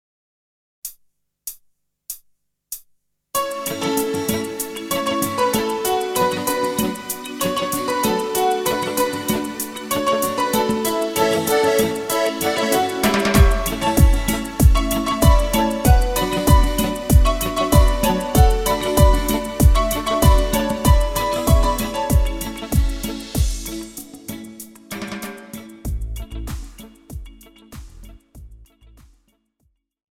Žánr: Pop
BPM: 96
Key: A
MP3 ukázka